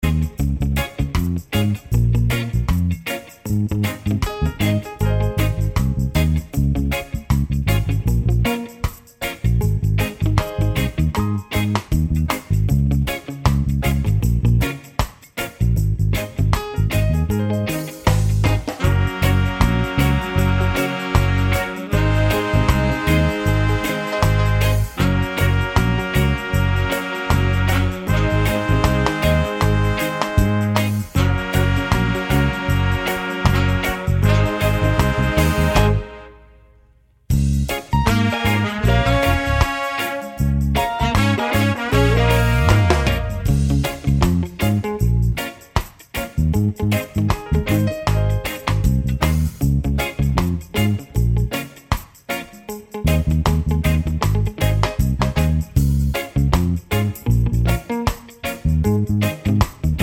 no Backing Vocals Ska 4:17 Buy £1.50